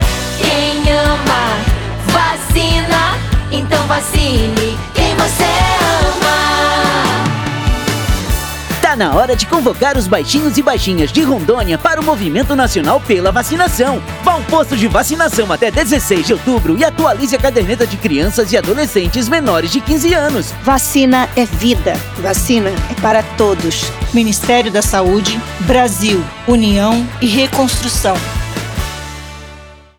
Áudio - Spot 30seg - Campanha de Multivacinação em Rondônia - 1,1mb .mp3